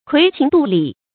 揆情度理 注音： ㄎㄨㄟˊ ㄑㄧㄥˊ ㄉㄨㄛˊ ㄌㄧˇ 讀音讀法： 意思解釋： 揆、度：揣測、估計；情、理：人的常情和事理。